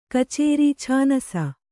♪ kacēri chānasa